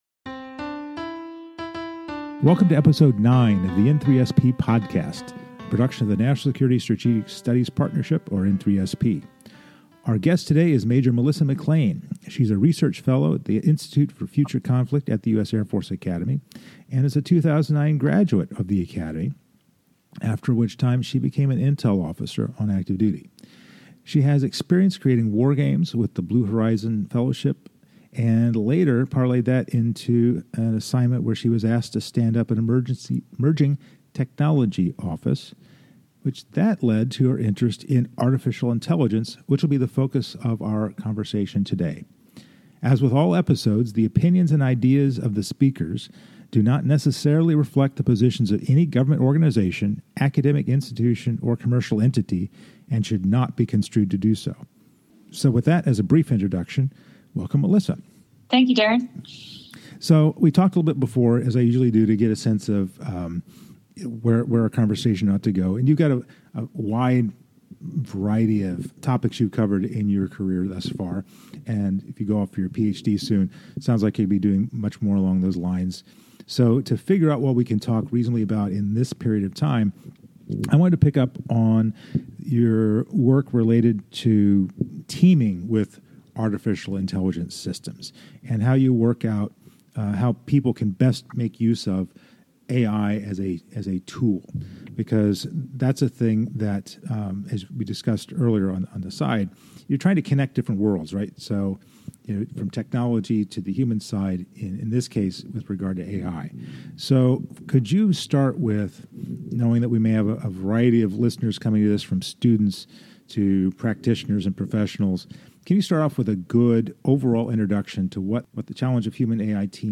The N3SP Podcast is a series of conversations on national security topics and careers with experts from universities, government organizations, and the private sector which are part of the overall National Security Strategic Studies Partnership.